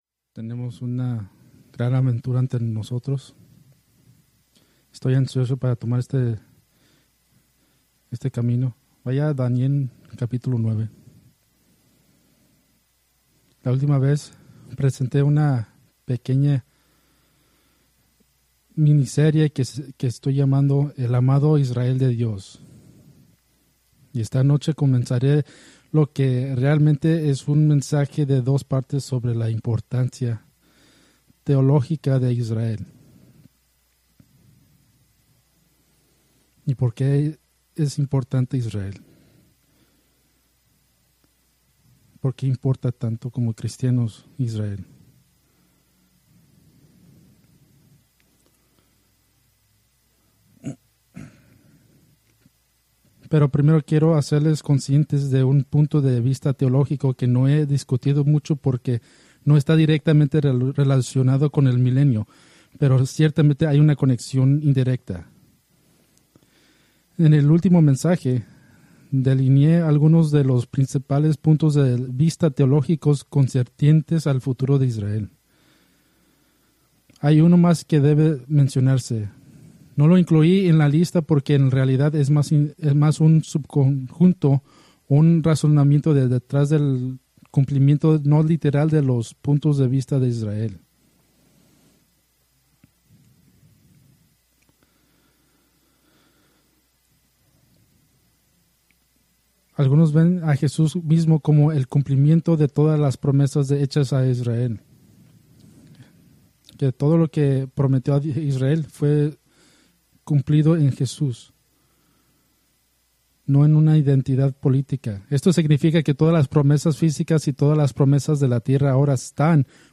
Preached March 23, 2025 from Escrituras seleccionadas